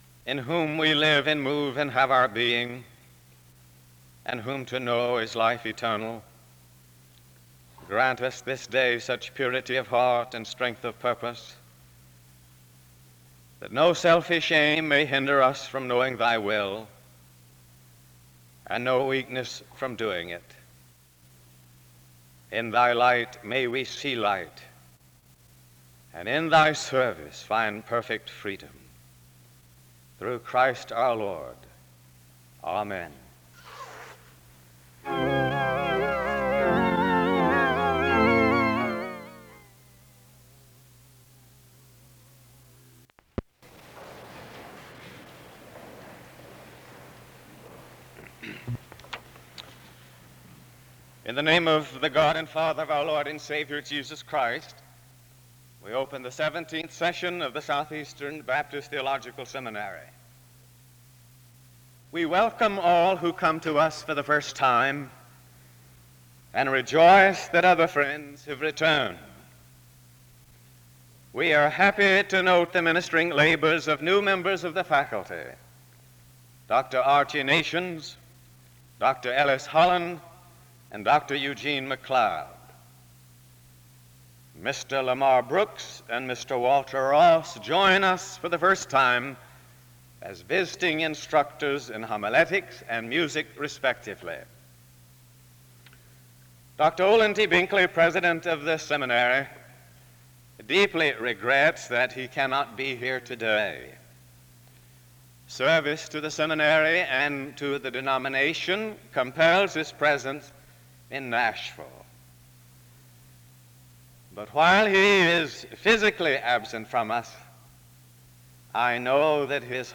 The service starts with an opening word of prayer from 0:00-0:33. All those in attendance are welcomed to the first chapel of the new semester from 0:51-2:04. Music plays from 2:13-7:00.